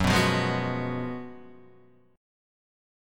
F# Major 7th Suspended 2nd Suspended 4th